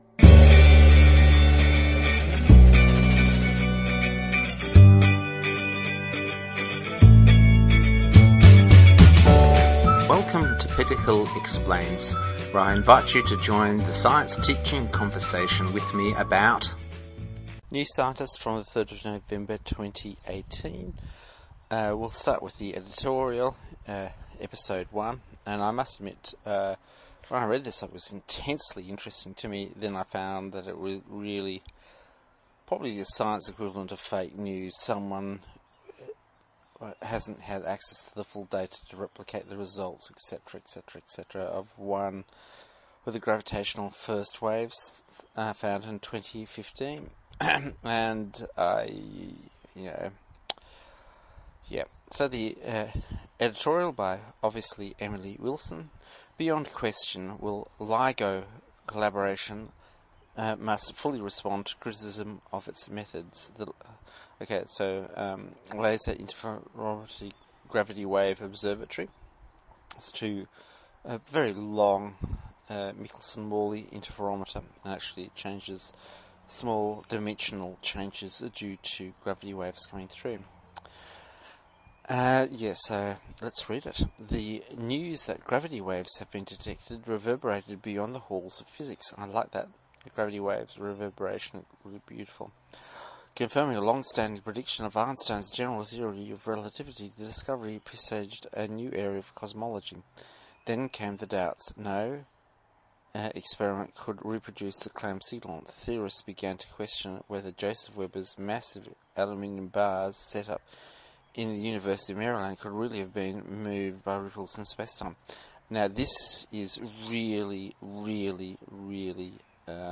Touching on rehab, I really yawn a lot towards the end and this, may I explain is nothing to do with the articles.